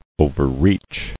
[o·ver·reach]